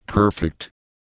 Worms speechbanks
Perfect.wav